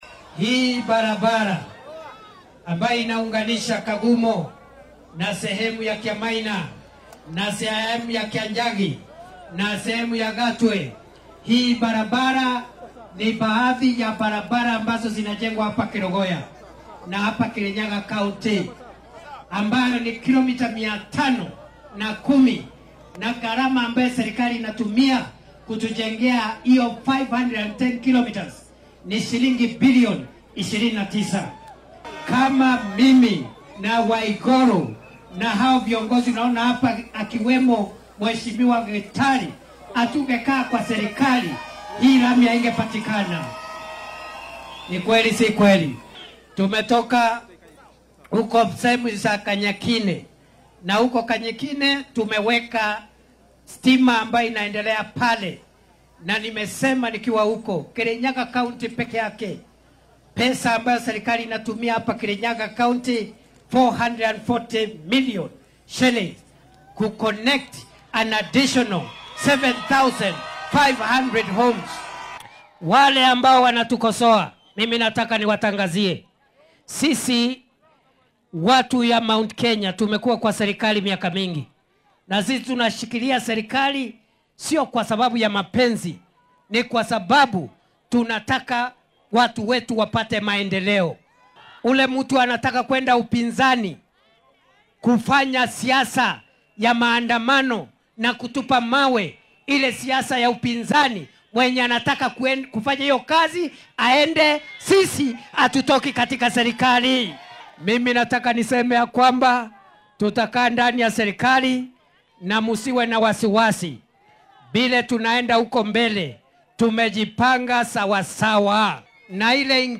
Kindiki ayaa hadalkan ka sheegay xilli uu kormeer iyo furitaan ku sameynayay mashruucyo horumarineed oo ka socda deegaanka bartamaha Kirinyaga, kuwaas oo ay ka mid ahaayeen mashruuca korontada ee Gatarwa iyo waddooyin la dhisayo.